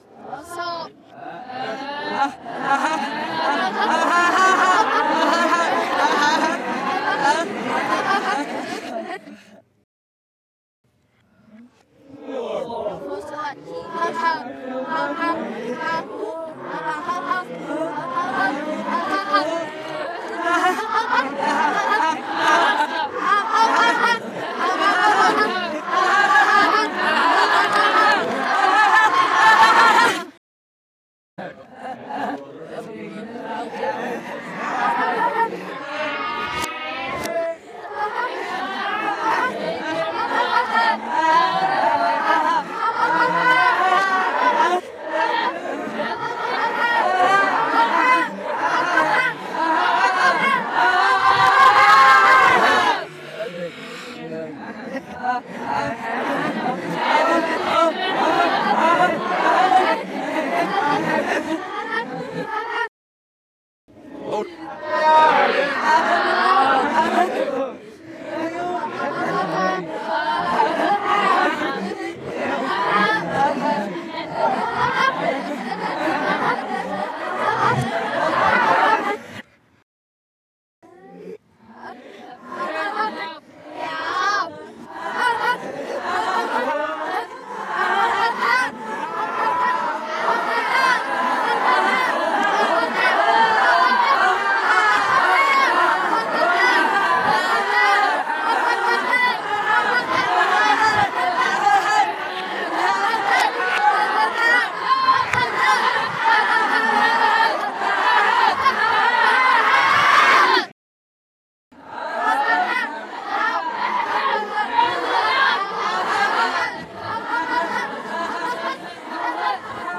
Black eyed children laughing sound effect
Children Evil Laughing Reverse Spooky sound effect free sound royalty free Funny